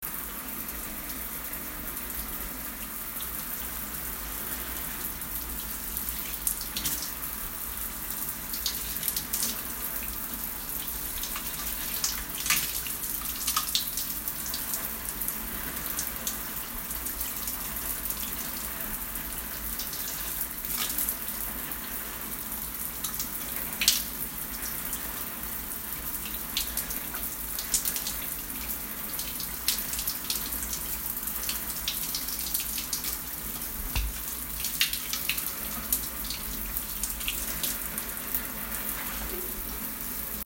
Shower Sounds
The shower water running against the wall and splashing onto the floor. A very calm relaxing recording.
Field-Recording-1-2.mp3